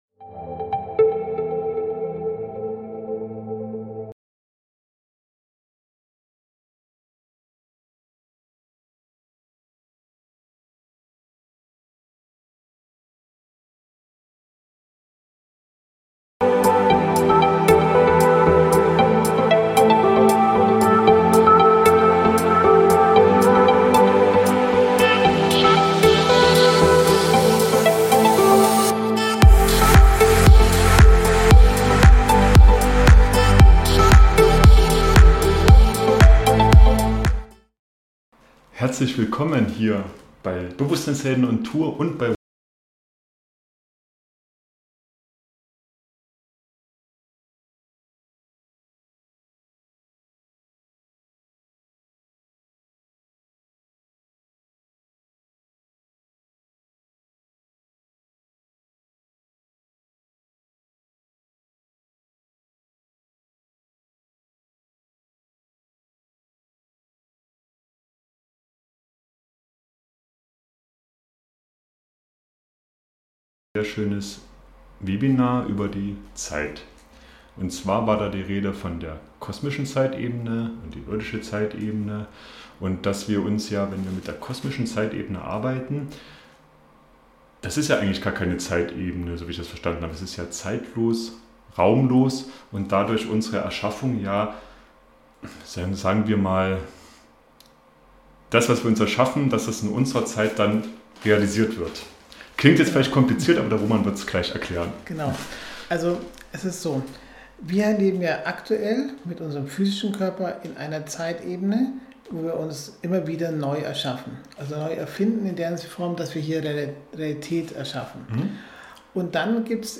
Dort konnten wir direkt neue Interviews aufnehmen.
Wir wünschen euch viel Freude beim "zeitlosen" Gespräch.